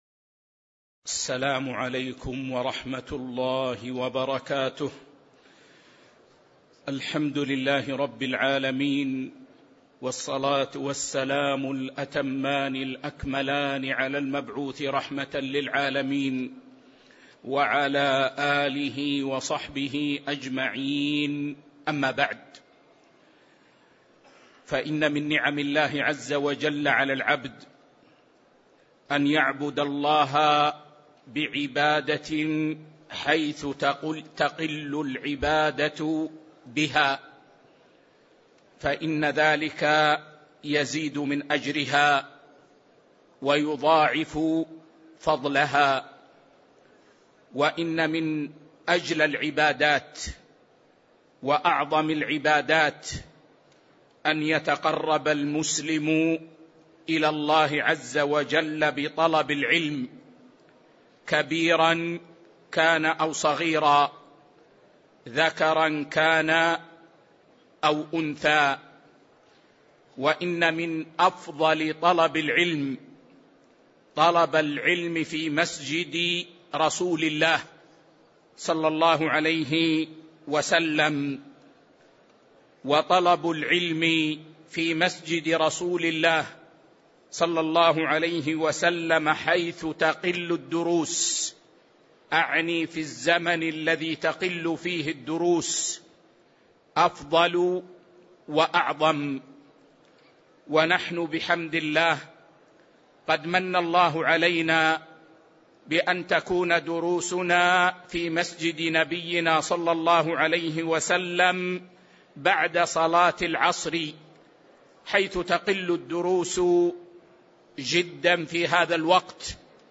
تاريخ النشر ٢٢ صفر ١٤٤٥ هـ المكان: المسجد النبوي الشيخ